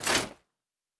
Foley Armour 10.wav